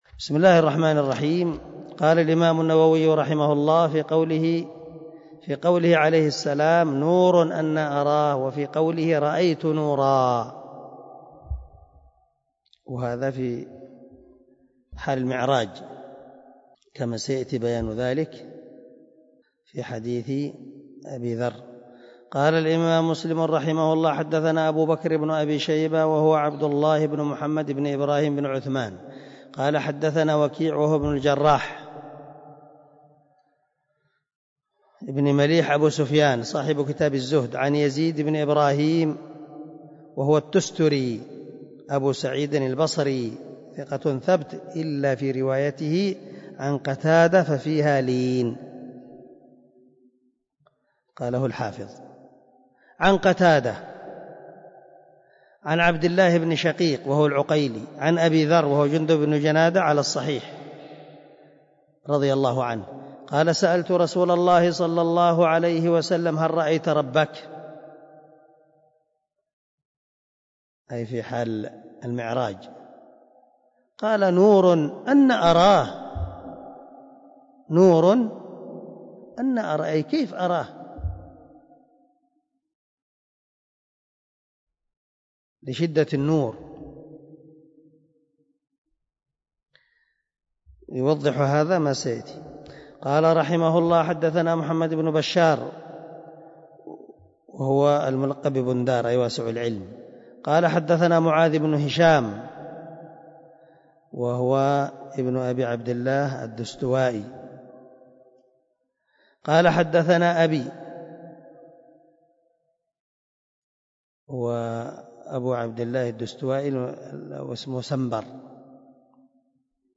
134الدرس 133 من شرح كتاب الإيمان حديث رقم ( 178 ) من صحيح مسلم
دار الحديث- المَحاوِلة- الصبيحة.